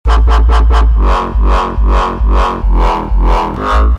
罗斯科风格的无声低音
描述：俄罗斯风格的节拍
标签： 140 bpm Dubstep Loops Bass Loops 677.72 KB wav Key : Unknown
声道立体声